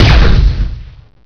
plasma_expl.wav